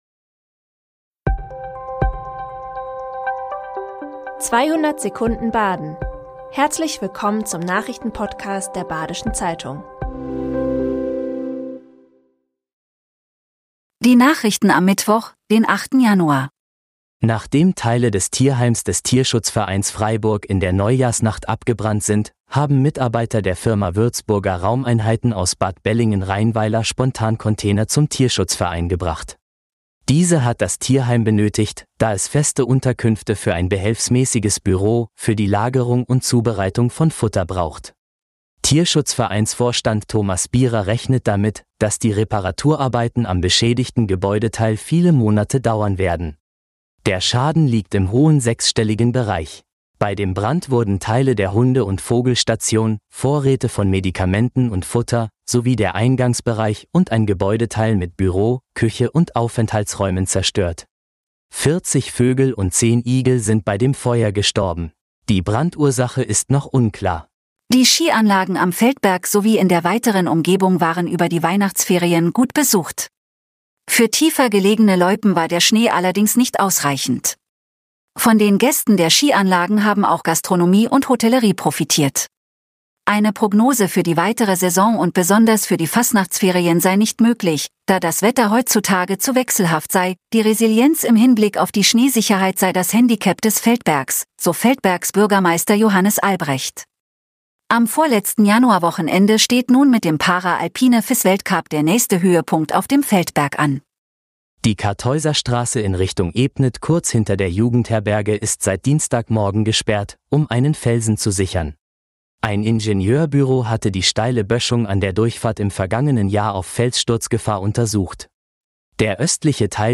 Die Nachrichten werden Ihnen in 200 Sekunden von einer künstlichen Computerstimme vorgelesen.